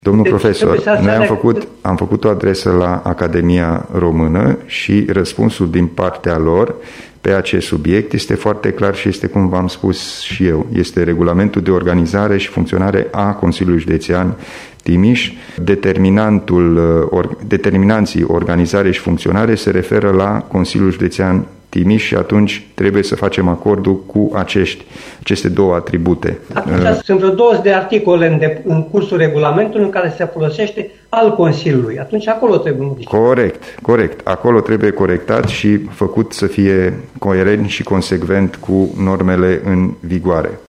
Sedinta de plen a Consiliului Judetean Timis
Președintele CJ Timiș a explicat că pentru a fi siguri de varianta corectă a formulării a fost solicitat un punct de vedere și din partea Academiei Române.